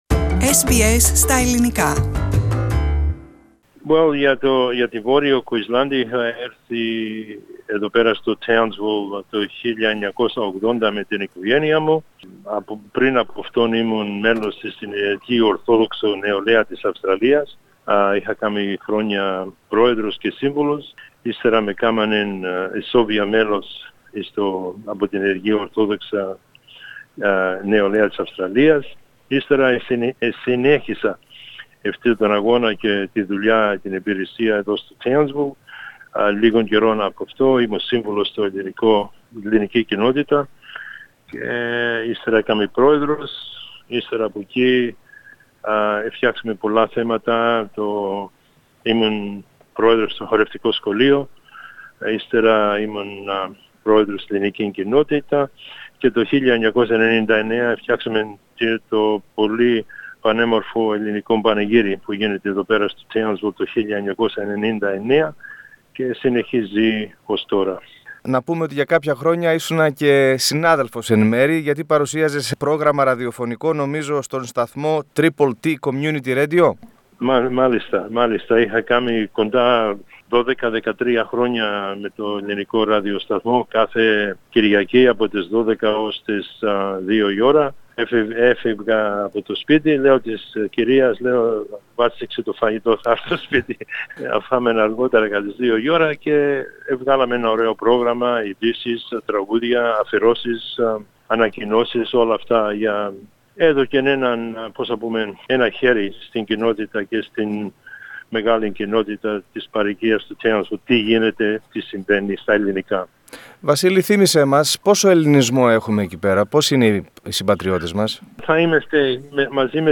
Ακούστε ολόκληρη την συνέντευξη πατώντας play στο podcast που συνοδεύει την κεντρική φωτογραφία ΣΧΕΤΙΚΟ ΑΡΘΡΟ Ελληνοαυστραλούς βραβεύει η αυστραλιανή πολιτεία Follow SBS Greek on Facebook Listen to SBS Greek Podcasts here Share